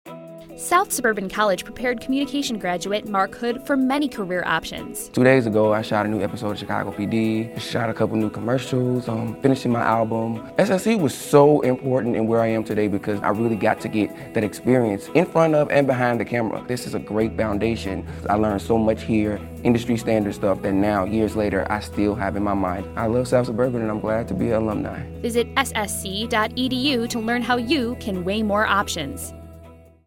Audio Spots
Radio spot utilizing student testimonial mixed with a voice-over talent promoting South Suburban College.